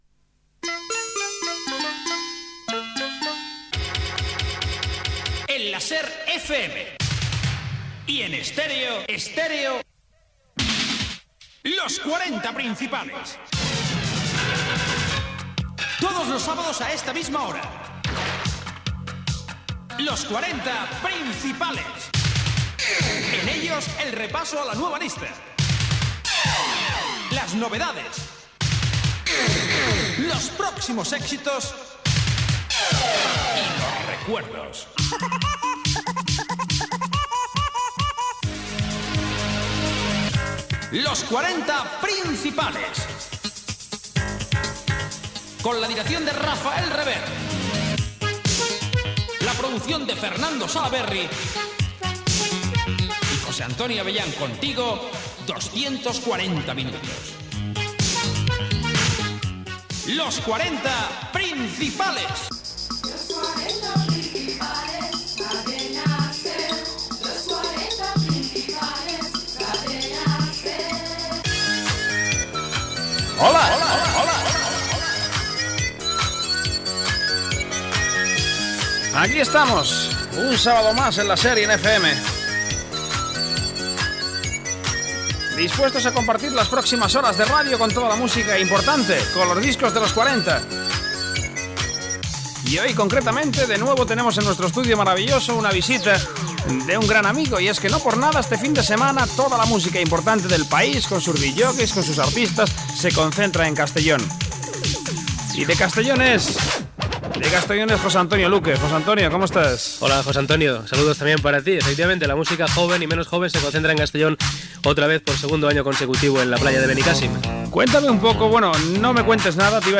Careta del programa, equip, presentació, connexió amb Castelló i presentació del número 1 de la llista
Musical